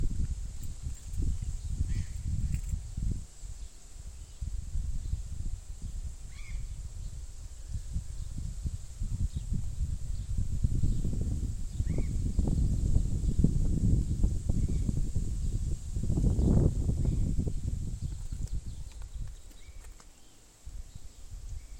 Cyanoliseus patagonus andinus
English Name: Burrowing Parrot
Location or protected area: Santa María
Condition: Wild
Certainty: Recorded vocal
loro-barranqueromp3.mp3